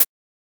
Closed Hats
edm-hihat-26.wav